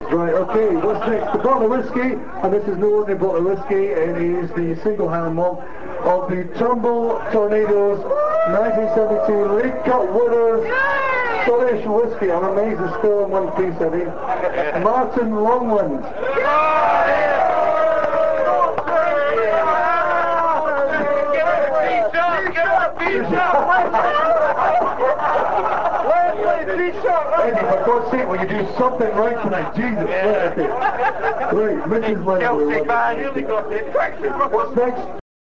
London Hibs Annual Burns Night Supper was held on Saturday 22nd January 2000 at the Kavanagh's Pub, Old Brompton Road.